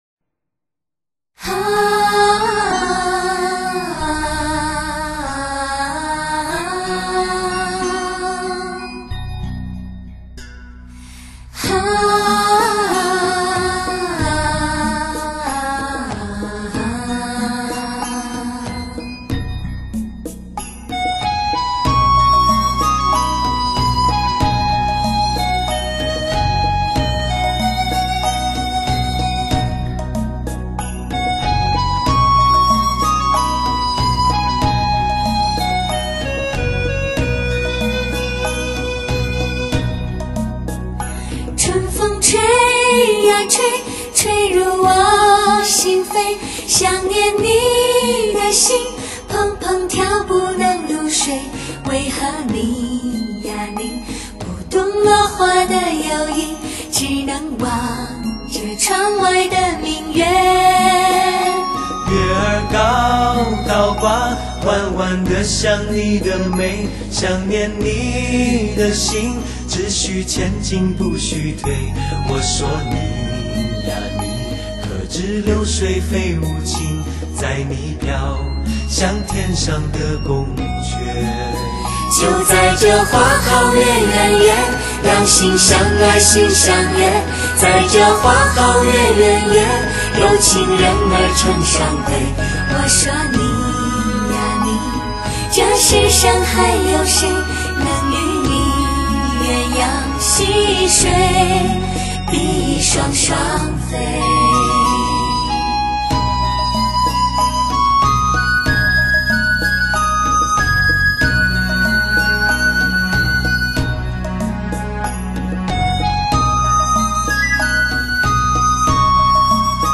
该专辑均为男女声合唱、对唱，多声部创新方式演绎。
人声甜润，音效合谐。
乐风清新配合简单的伴奏把人声发挥的淋漓尽致。